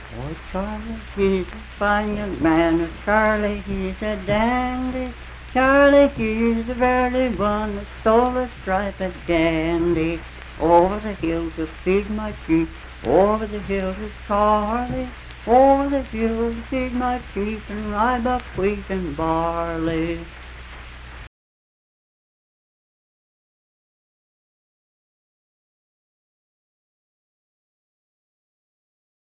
Unaccompanied vocal music performance
Verse-refrain 1(8).
Dance, Game, and Party Songs
Voice (sung)